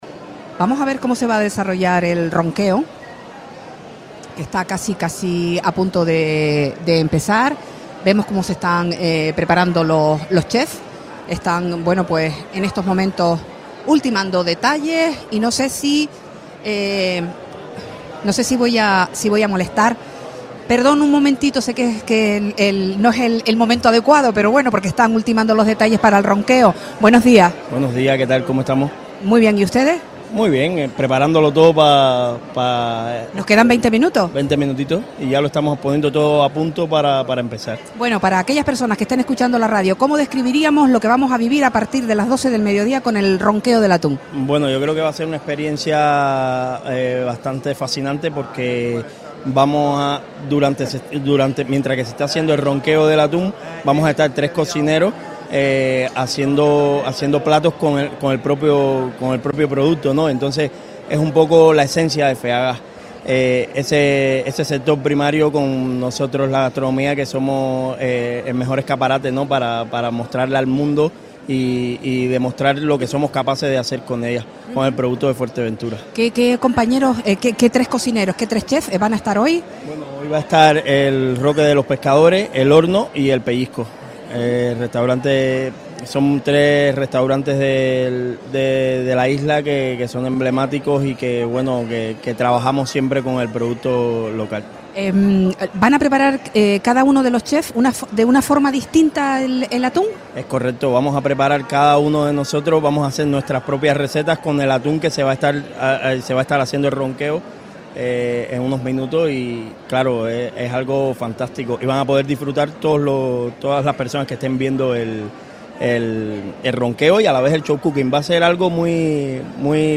Los cocineros encargados del ronqueo del atún en Feaga 2026 atienden a Radio Sintonía en la previa de uno de los eventos que más miradas roba en la feria Deja un comentario
Entrevistas